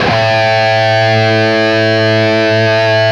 LEAD A 1 CUT.wav